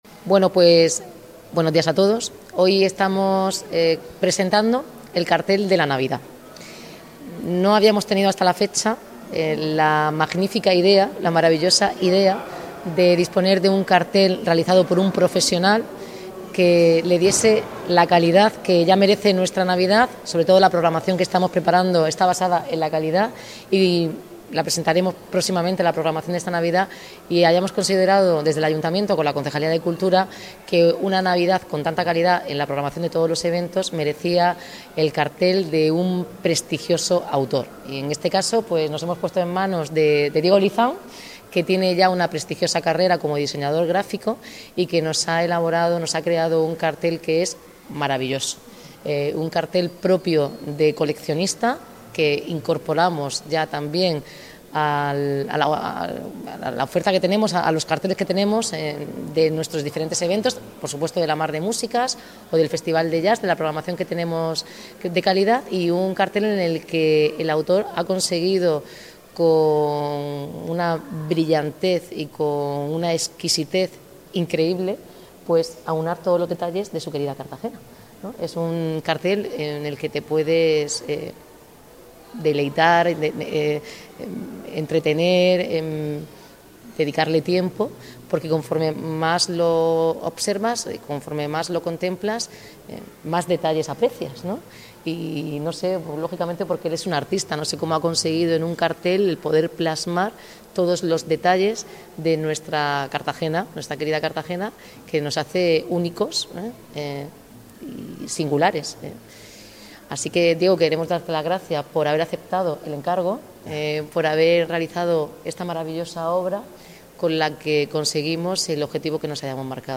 Enlace a Declaraciones de la alcaldesa, Noelia Arroyo